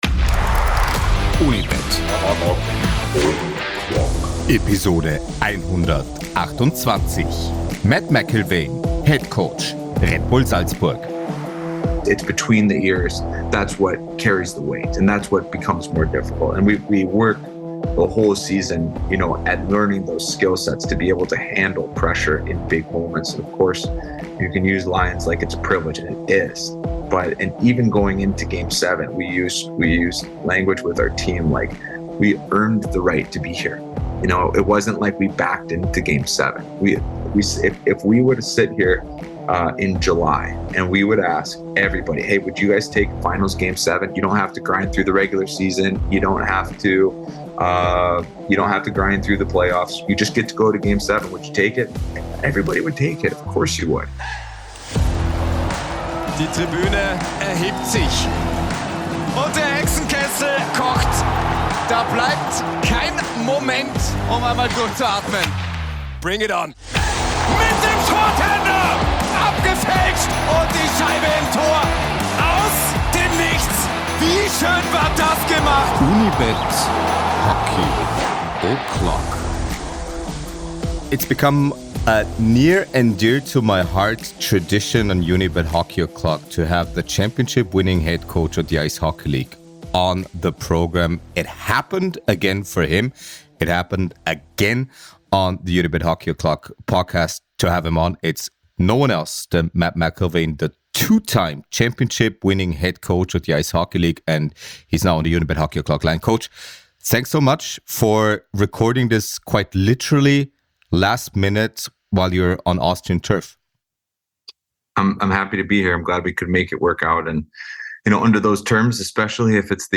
win2day Hockey O'Clock ist der Eishockey-Podcast über das österreichische Eishockey, die win2day ICE Hockey League aber auch internationale Eishockey-Ligen. In ausführlichen Interviews mit Tiefgang kommen Spielerinnen und Spieler, Trainerinnen und Trainer sowie Funktionärinnen und Funktionäre im nationalen und internationalen Umfeld zu Wort.